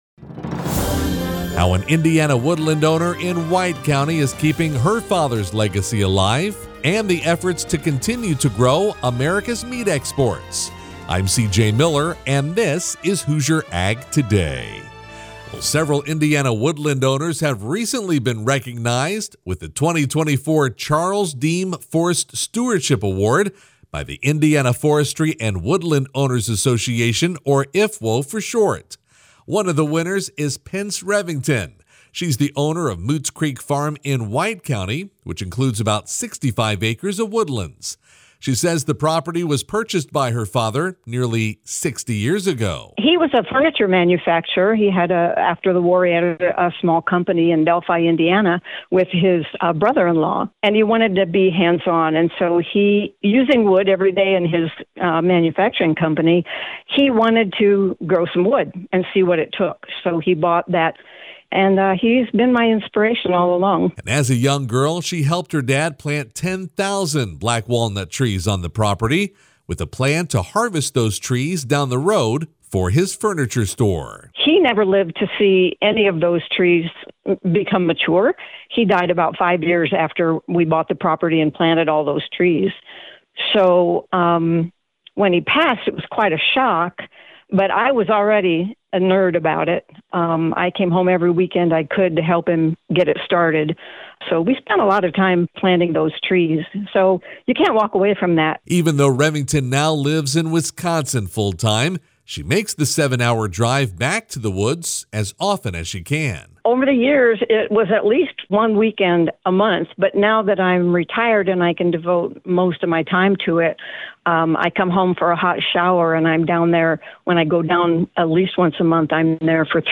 radio news feature